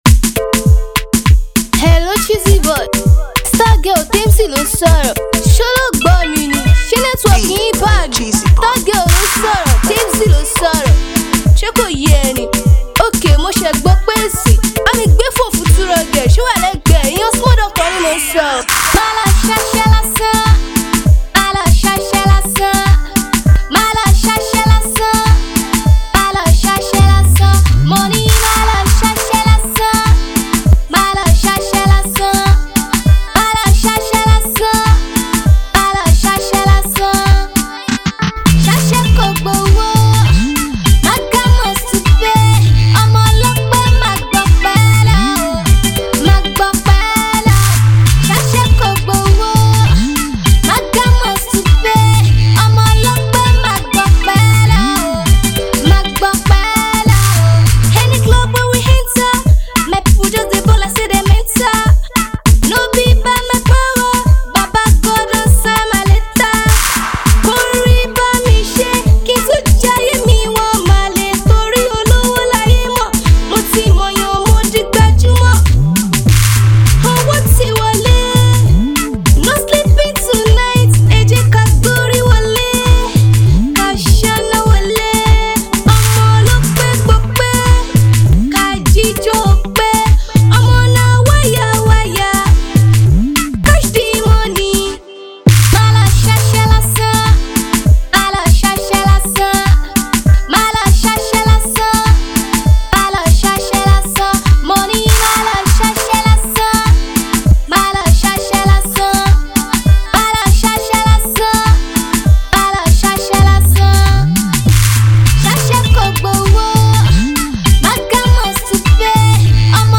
female singer
club jam single